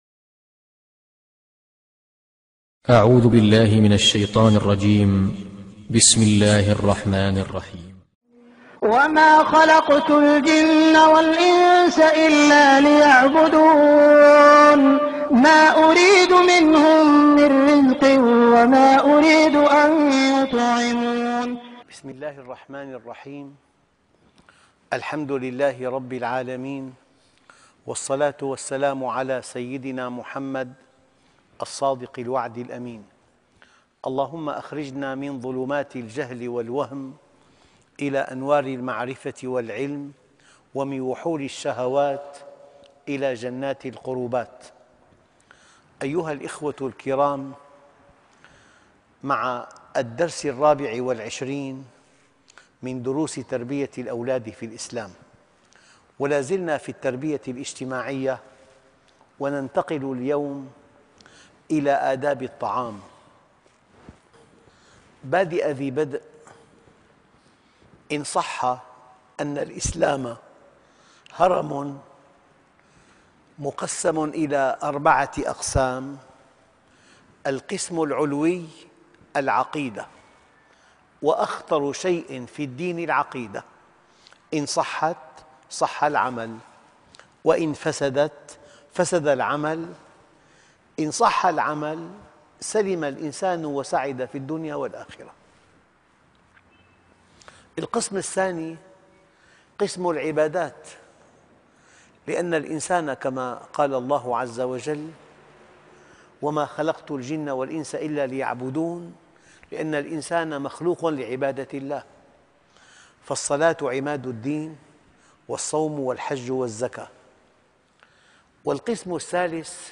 ( الدرس 24 ) التربية الإجتماعية